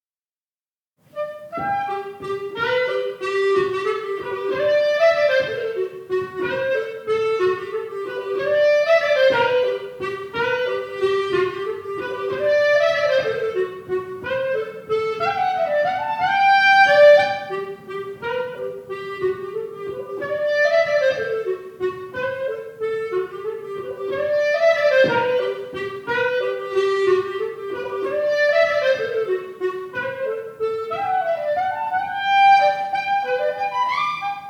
Vals
Inspelad: Allégården - 2001